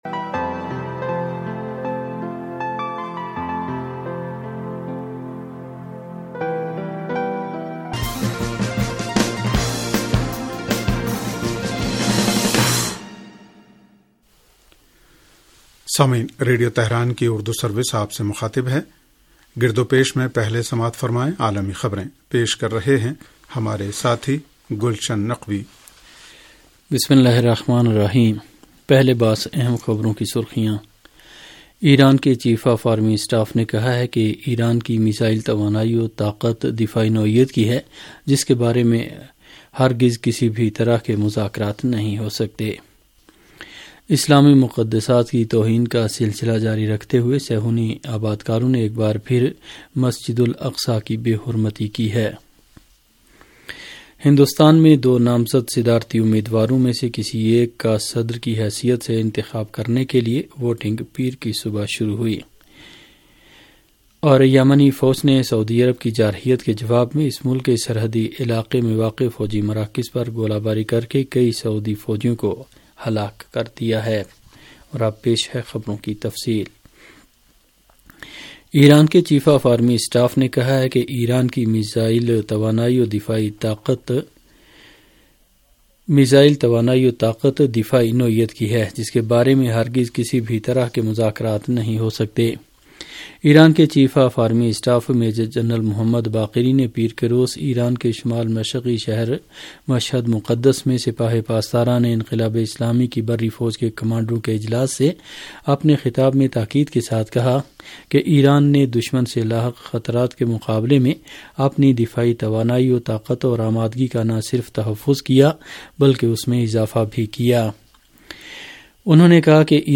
ریڈیو تہران کا سیاسی پروگرام گردوپیش و عالمی خبریں